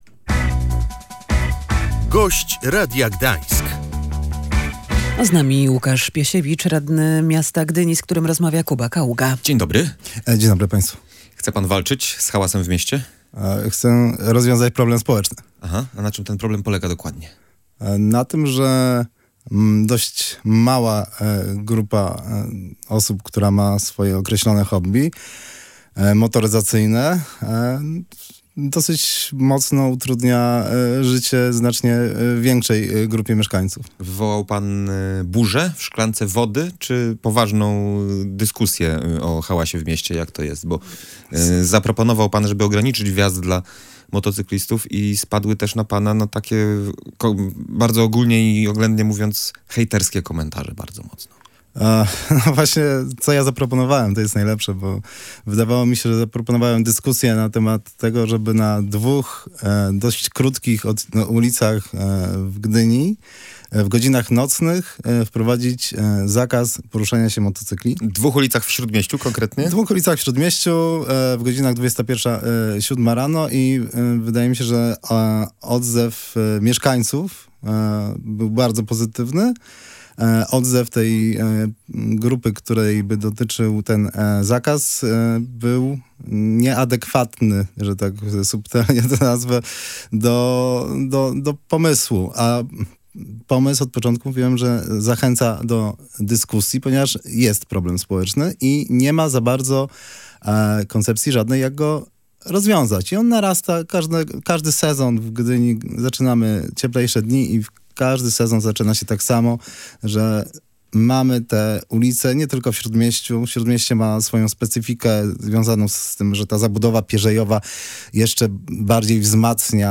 Radny Łukasz Piesiewicz mówił w Radiu Gdańsk, że chodzi o szereg skoordynowanych działań, które ograniczą hałas.